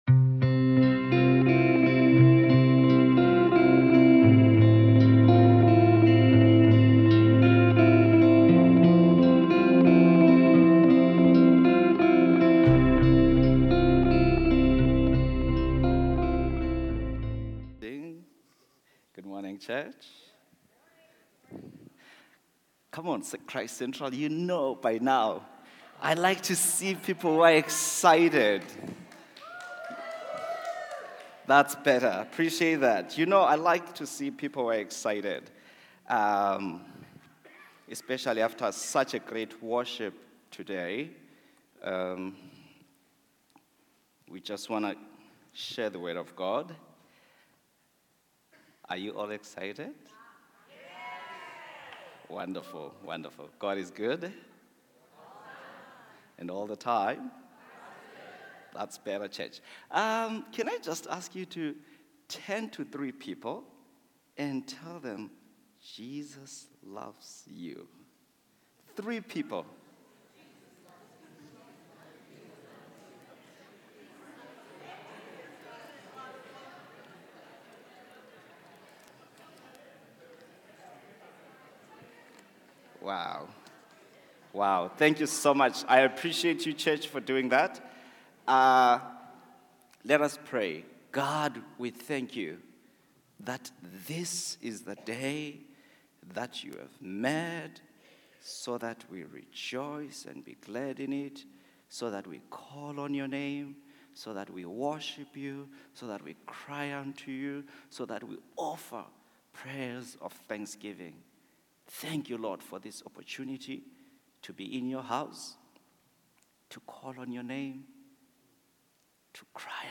Sermons | Christ Central Church